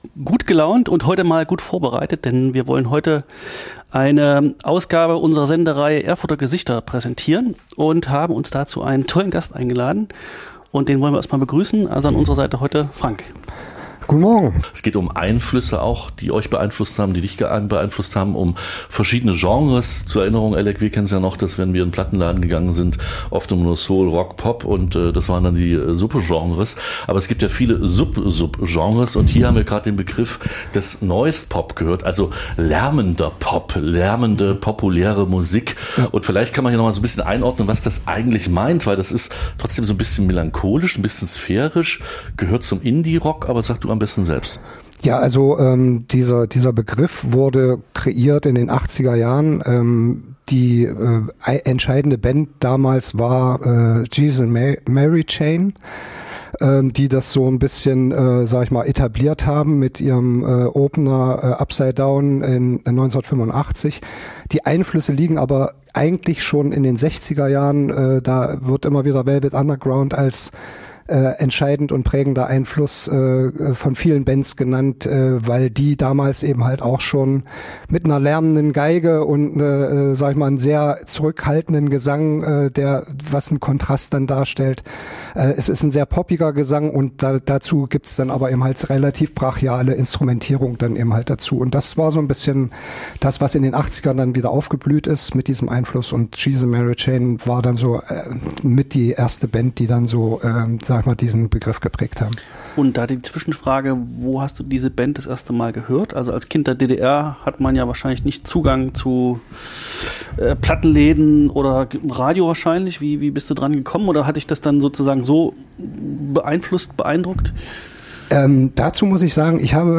Als die Musik noch richtig groß war - ein Gespräch